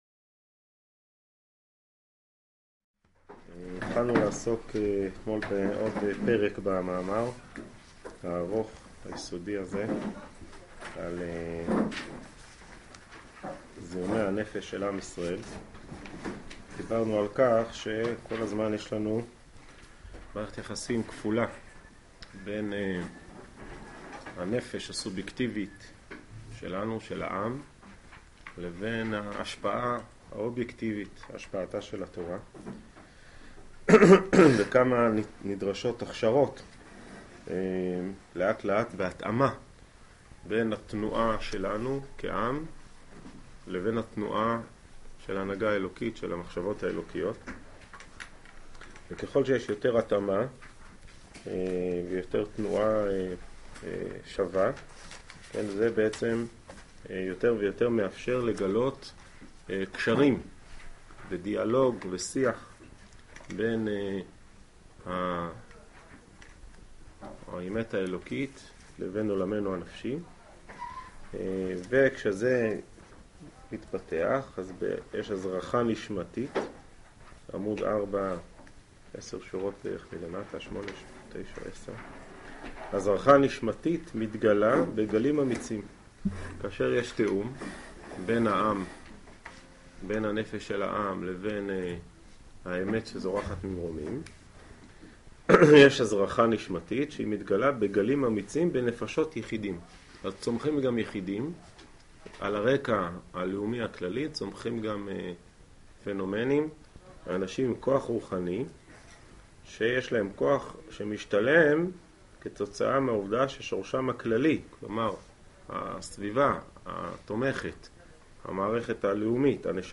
שיעור זה הוא חלק מ: מאמרי הראי"ה